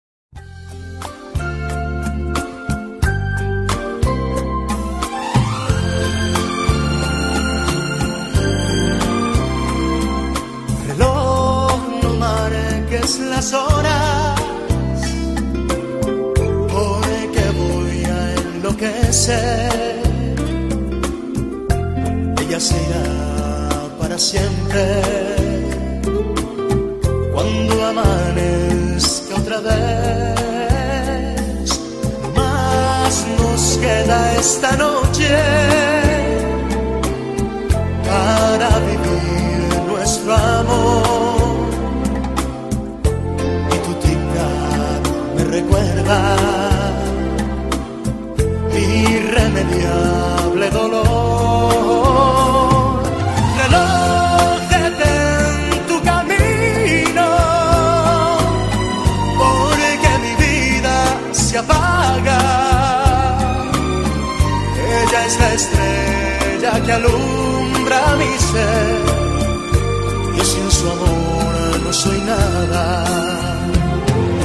Reloj.mp3